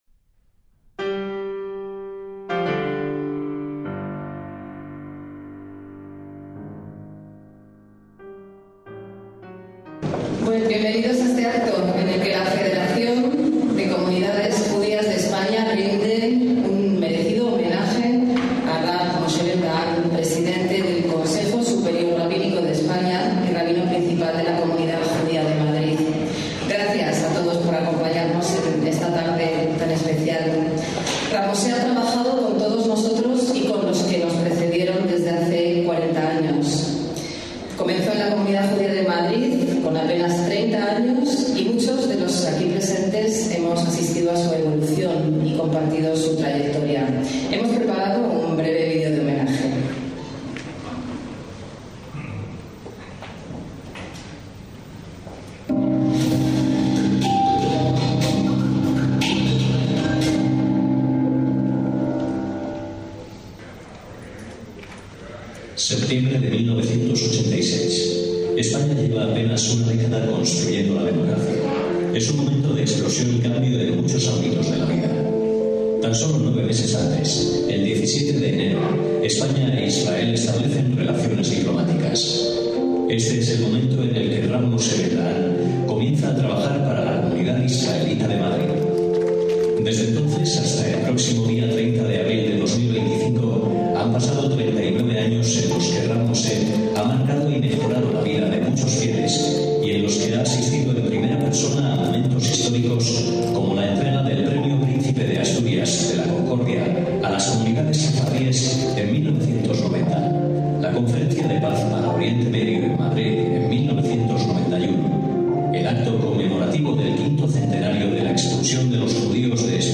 Acto completo del homenaje de la FCJE